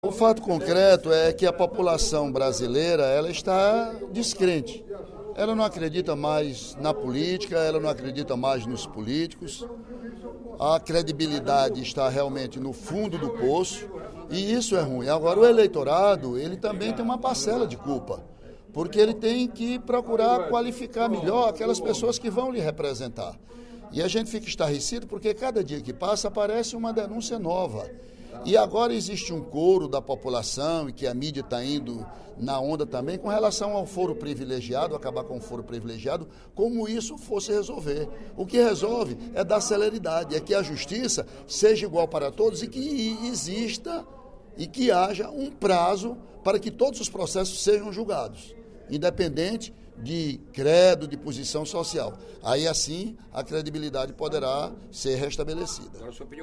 O deputado Ely Aguiar (PSDC) afirmou, nesta sexta-feira (03/03), no primeiro expediente da sessão plenária da Assembleia Legislativa, que há descrédito da população na classe política.